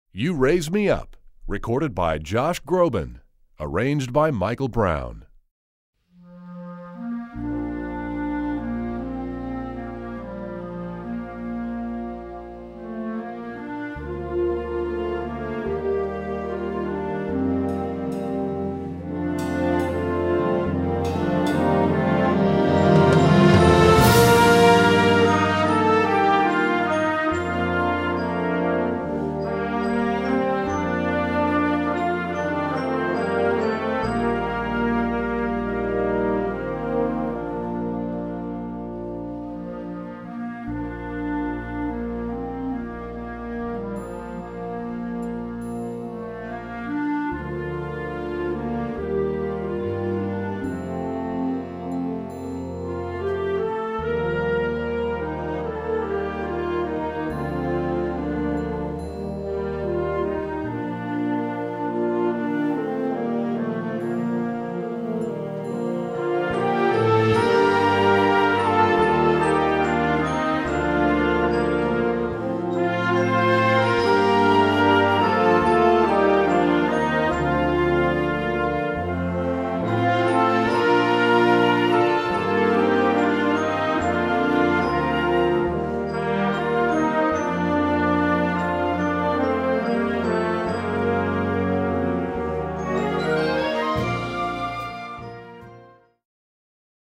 Gattung: Pophit für Blasorchester
Besetzung: Blasorchester
adapted for band